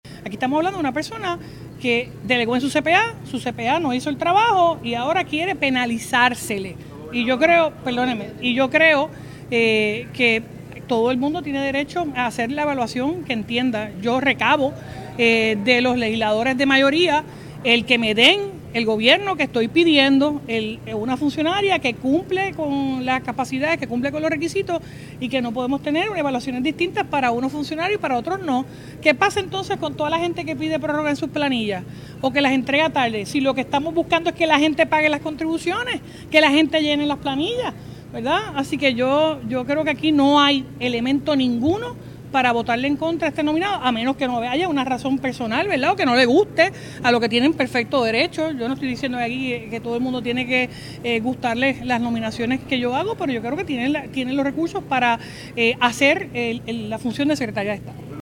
En conferencia de prensa, la primera mandataria destacó la gesta de Ferraiuoli al enfrentar la vista de unas cinco horas, y señaló cómo el pasado 30 de diciembre de 2024, cuando nominó a la secretaria, todo estaba en orden, por lo que indicó no entender por qué condenan que haya solicitado una prórroga.